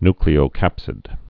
(nklē-ō-kăpsĭd, ny-)